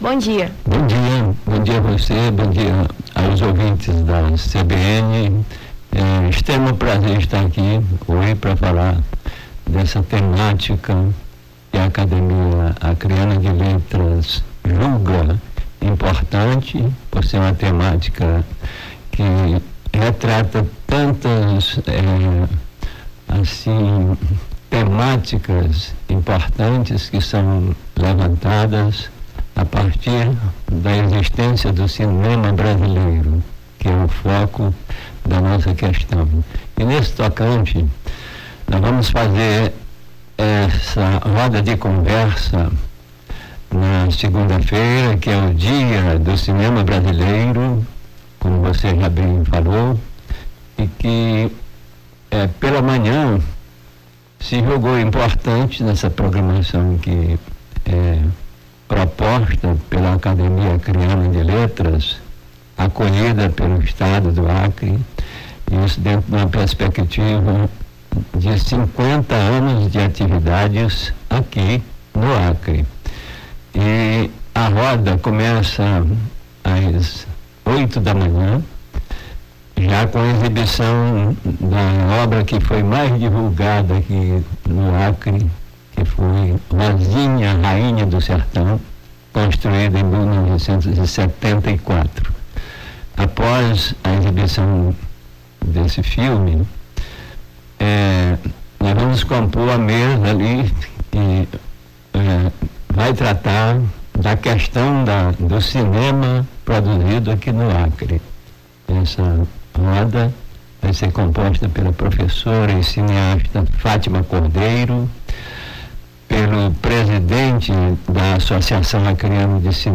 Baixar Esta Trilha Nome do Artista - CENSURA - ENTREVISTA (CINEMA BRASILEIRO) 16-06-23.mp3 Foto: Arquivo Web Facebook Twitter LinkedIn Whatsapp Whatsapp Tópicos Rio Branco Acre Cinema cinema brasileiro